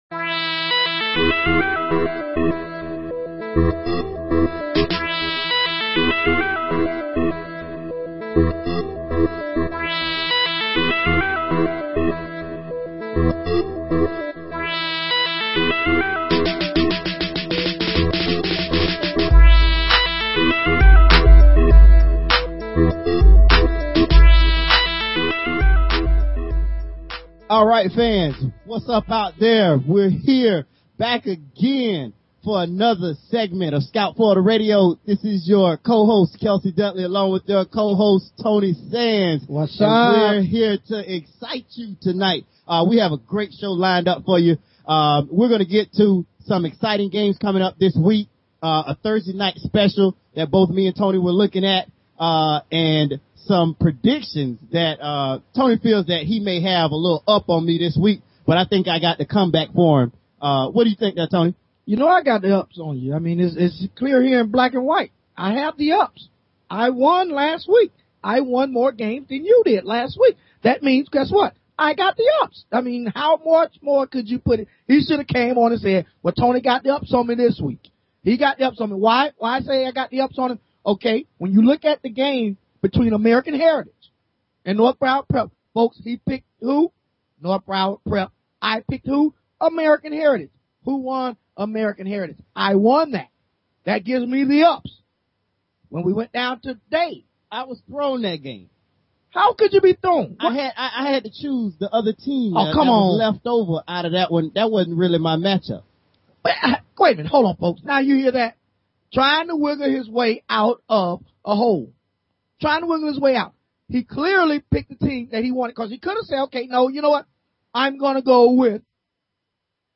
Talk Show Episode, Audio Podcast, Scout_Florida and Courtesy of BBS Radio on , show guests , about , categorized as